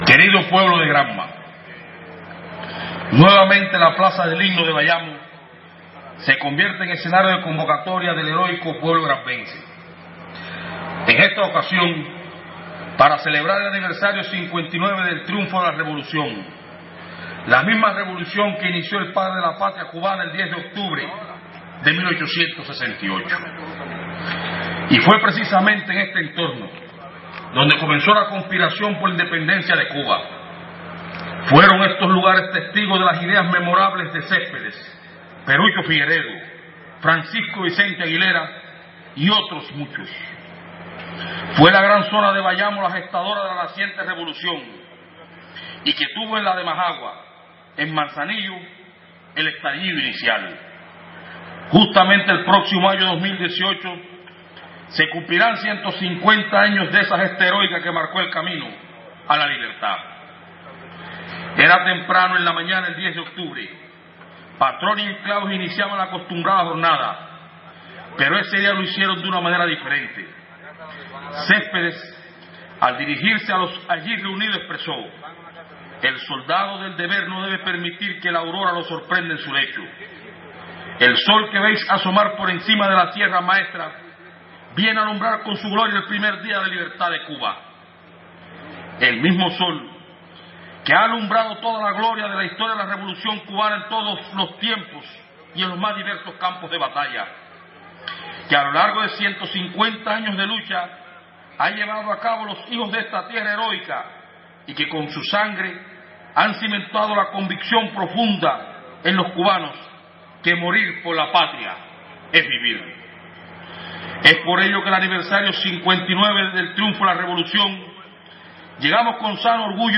El acto provincial por el aniversario 59 del Triunfo de la Revolución cubana aconteció este miércoles en la plaza del Himno de Bayamo, capital del suroriental territorio de Granma.
Discruso-pronunciado-por-Federico-Hernández-Primer-secretario-del-Partido-en-Granma1.mp3